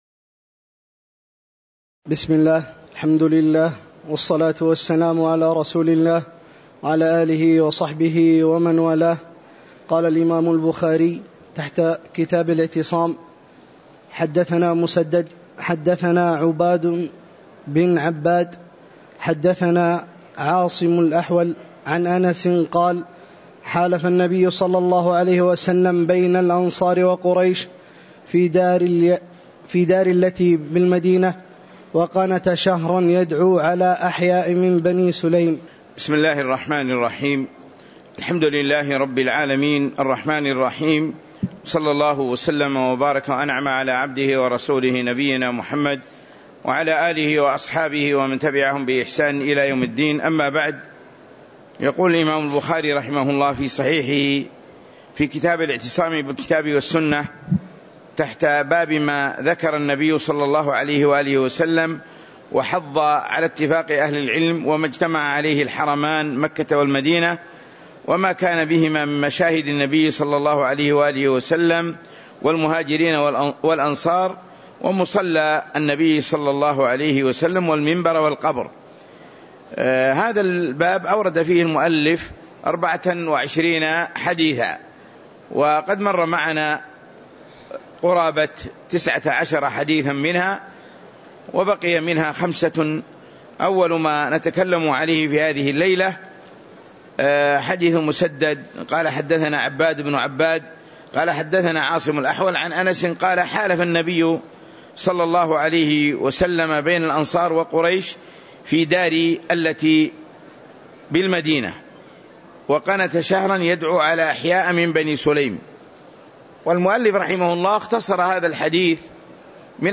تاريخ النشر ٢٨ جمادى الآخرة ١٤٤٦ هـ المكان: المسجد النبوي الشيخ